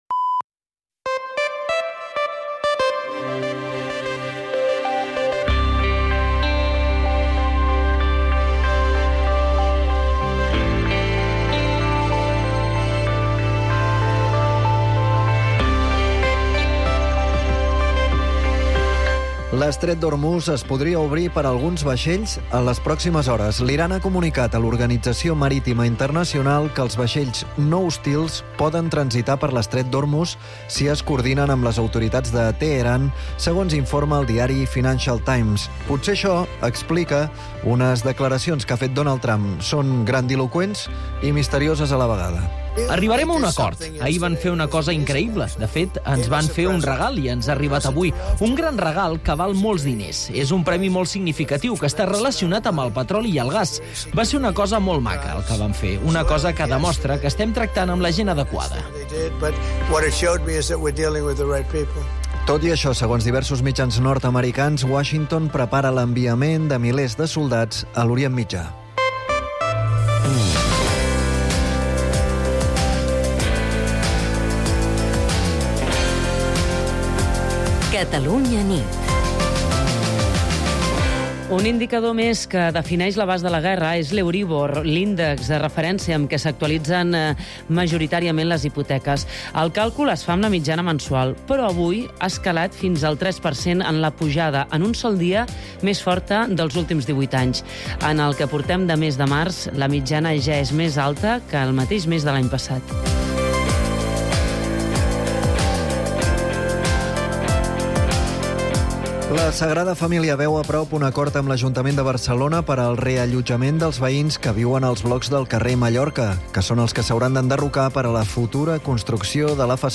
El compromís d'explicar tot el que passa i, sobretot, per què passa és la principal divisa del "Catalunya nit", l'informatiu nocturn de Catalunya Ràdio